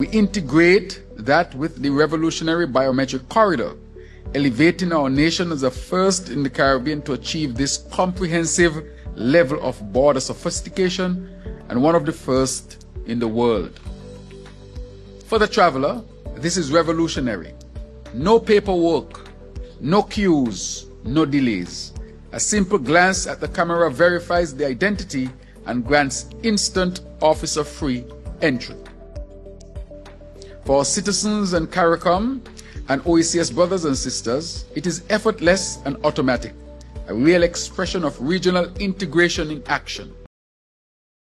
On June 3rd, 2025, the Biometric Corridor or Biocorridor was introduced to the eTA System. Prime Minister and Minister with responsibilities for National Security, the Hon. Dr. Terrance Drew stated its significance: